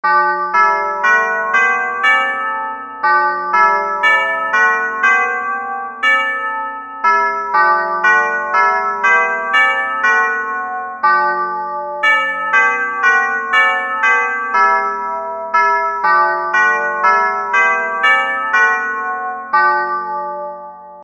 The Clock and Chimes of St Mary's Church, Westonzoyland.
The chimes were played by activating hammers which struck the five bells in the tower at that time.  Using the notes of the bells the score below was developed.